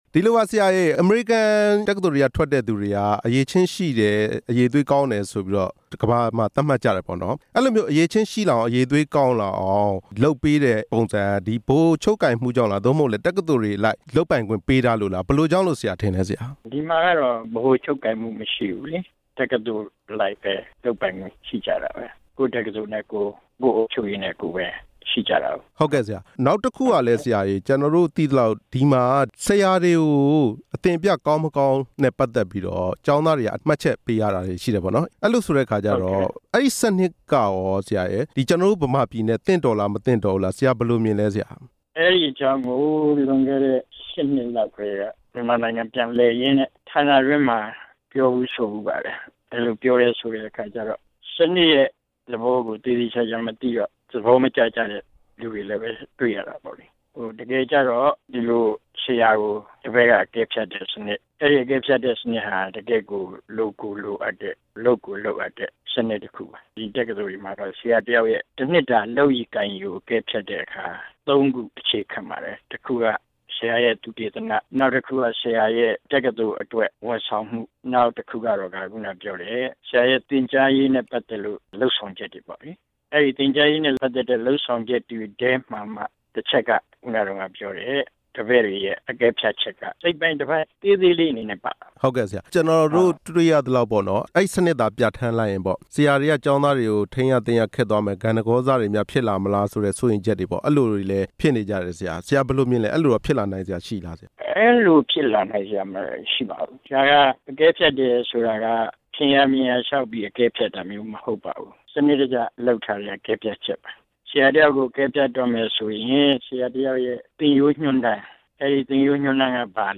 ပညာရေးဥပဒေပြင်ဆင်ပေးဖို့ အာဏာပိုင်တွေနဲ့ ပညာရေး ဝန်ကြီးဌာနတို့က လက်မခံတဲ့ အကြောင်းမေးမြန်းချက်